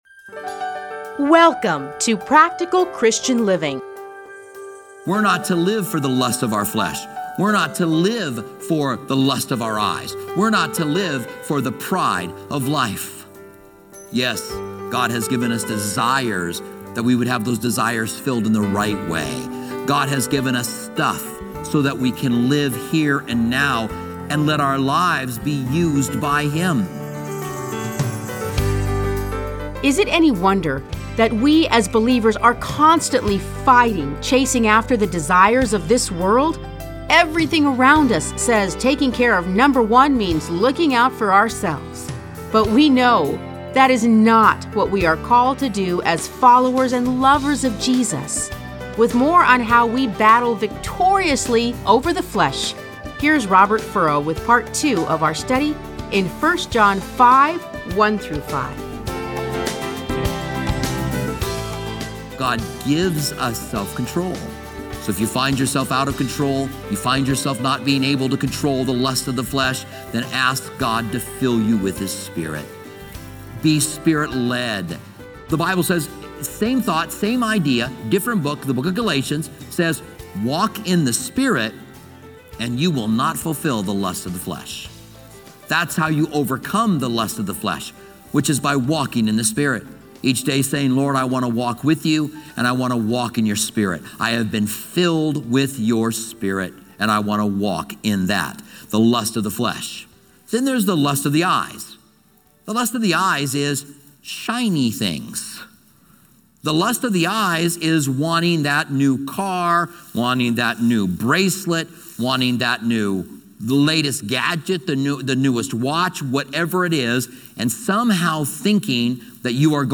Listen to a teaching from 1 John 5:1-5.